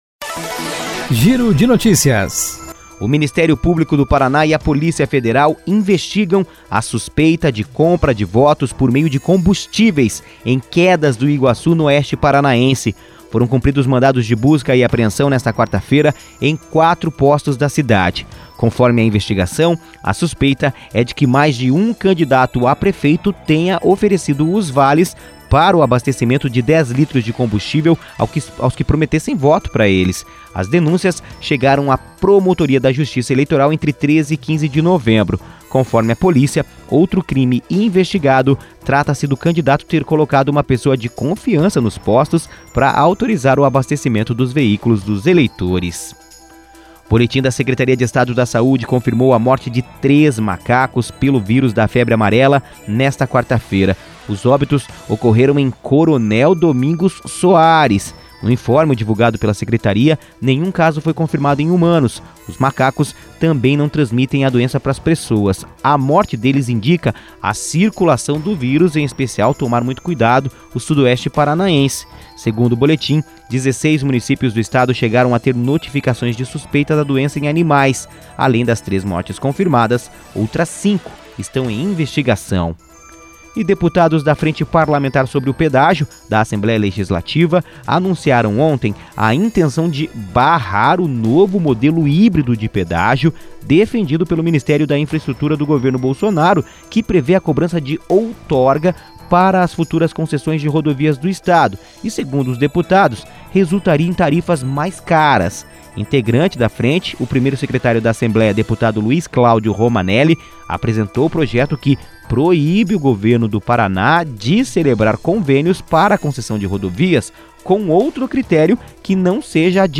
Giro de Notícias Manhã COM TRILHA